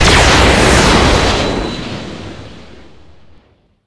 카툰 게임 사운드
Bonus_s_ef_ce_tomahawk_s.wav